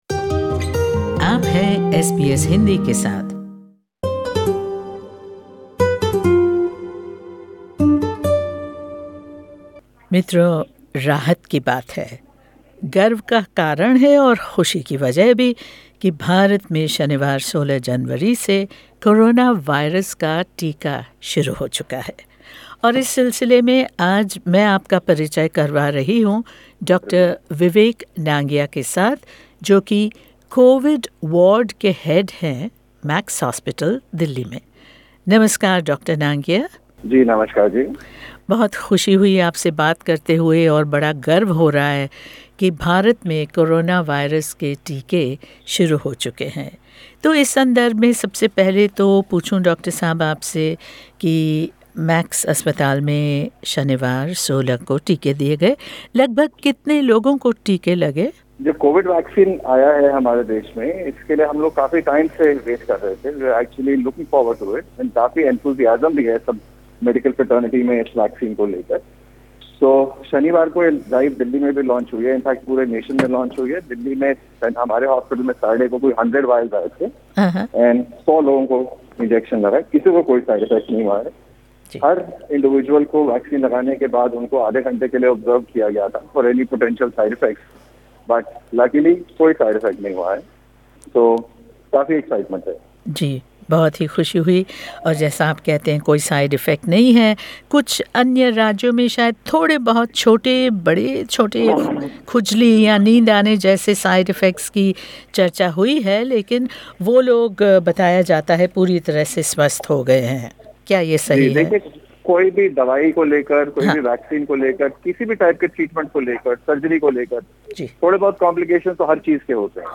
In this exclusive interview, he shares his experience with the administration of the first shots, the process of vaccine distribution and the road ahead.